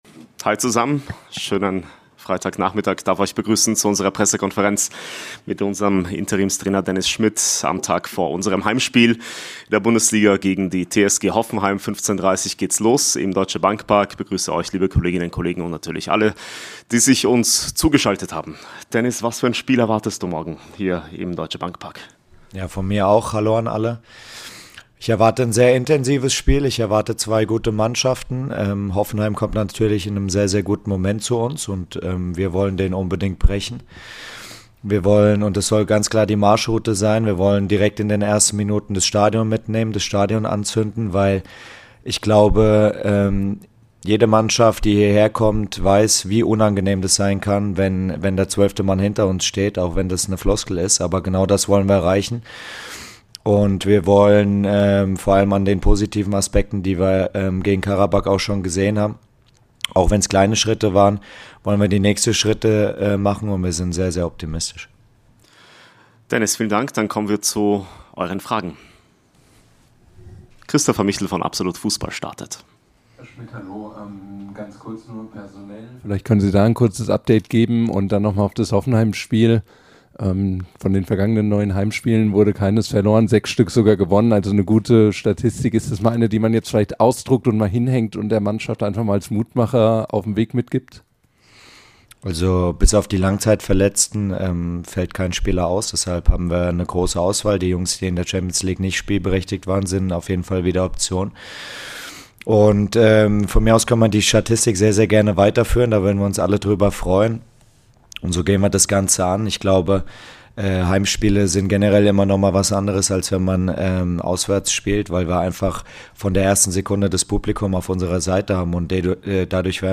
I Die Pressekonferenz vor Hoffenheim ~ Eintracht Aktuell Podcast